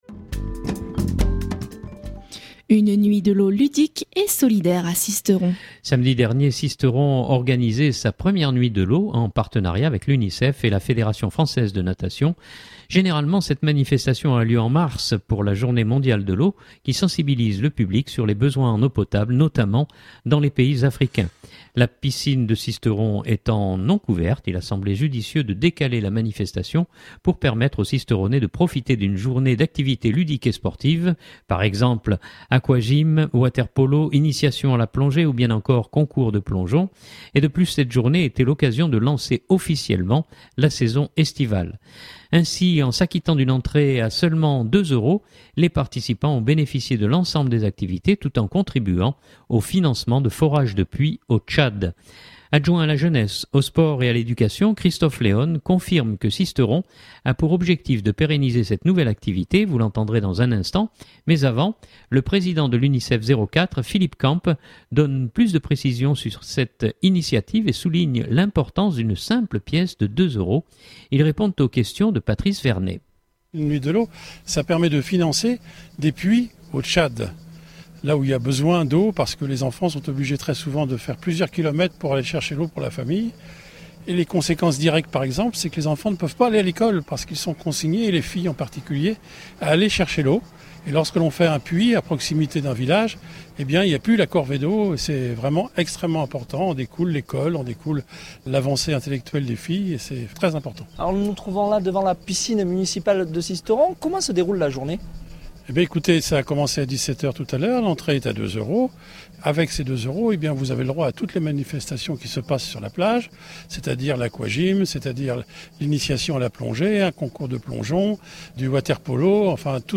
Adjoint à la jeunesse, aux sports et à l’éducation, Christophe Léone confirme que Sisteron a pour objectif de pérenniser cette nouvelle activité, vous l’entendrez dans un instant.